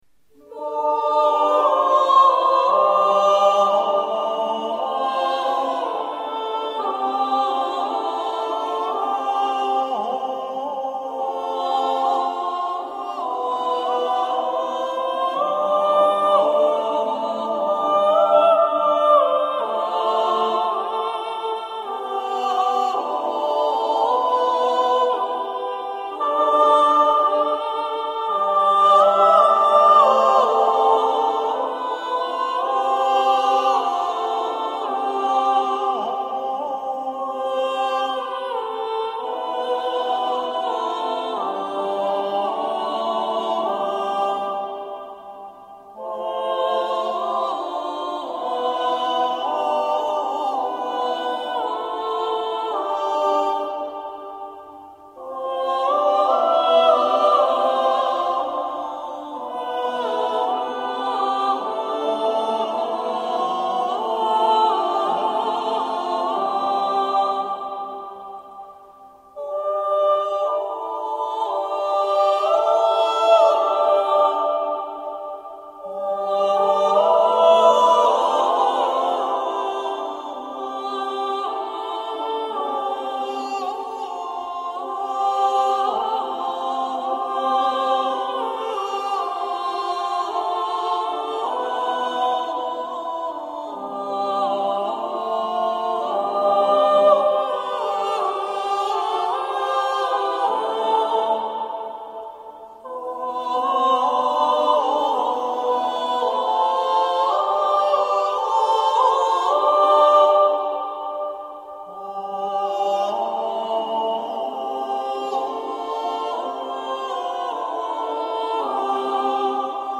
Clausula